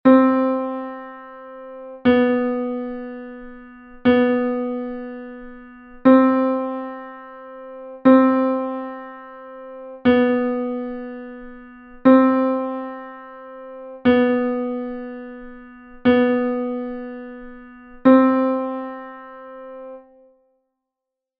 Exercise 5: low B-C diatonic semitone exercise.
ejercicio_semitono_diatonico_si-do_graves.mp3